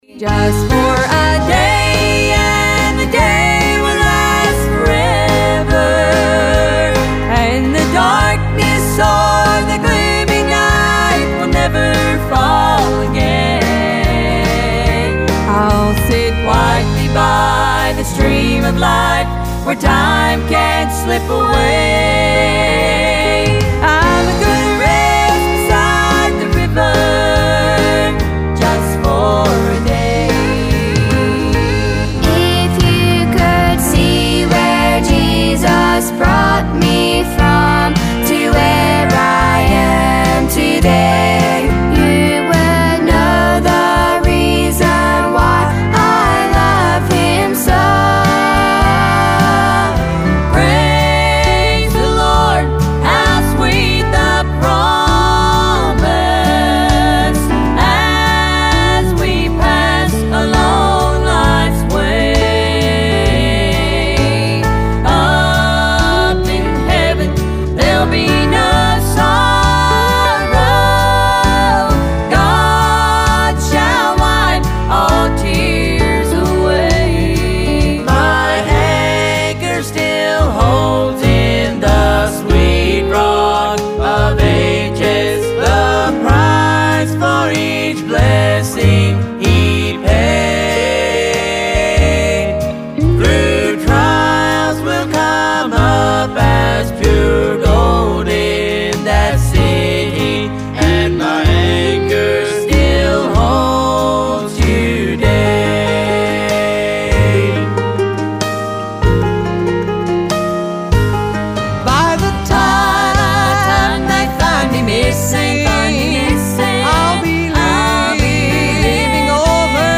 Celebrating 50 years of singing Southern Gospel Music!